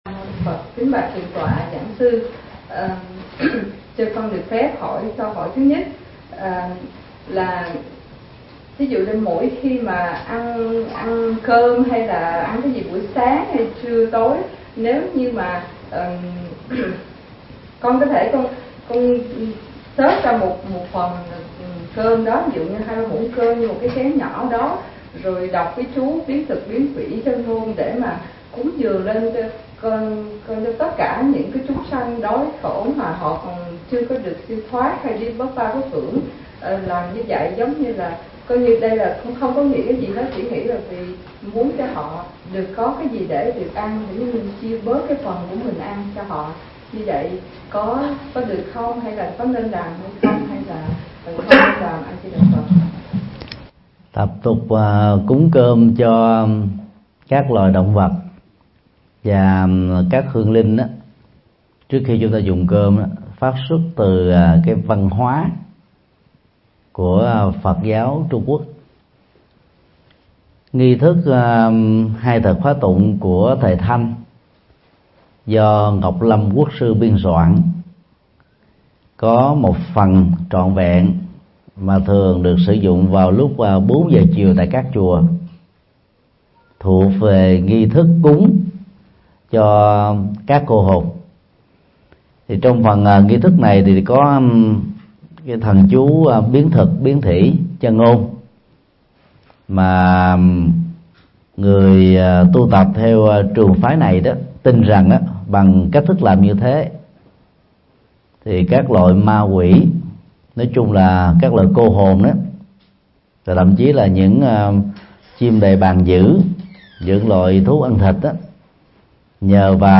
Vấn đáp: Hiểu cho đúng về cúng thí thực – Thầy Thích Nhật Từ mp3